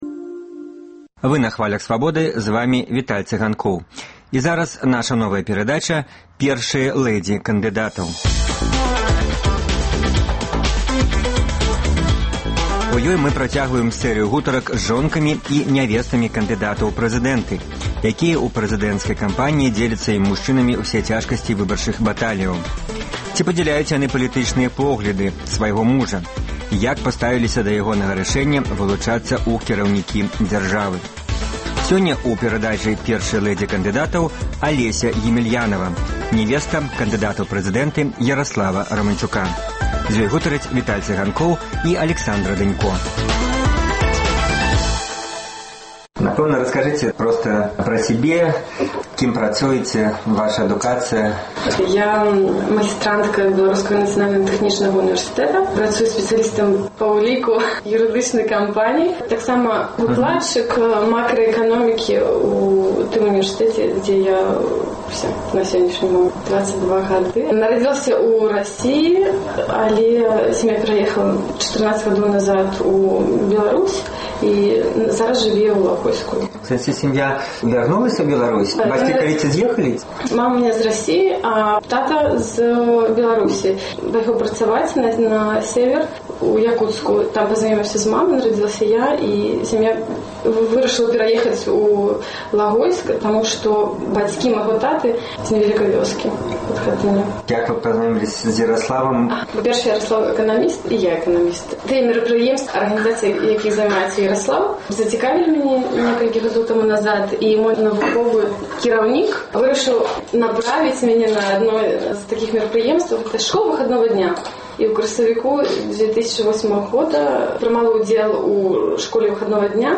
Мы працягваем сэрыю гутарак з жонкамі кандыдатаў у прэзыдэнты, якія напярэдадні выбараў добраахвотна ці вымушана дзеляць са сваімі мужчынамі ўсе цяжкасьці выбарчых баталіяў. Наколькі важная жаночая падтрымка для беларускіх кандыдатаў?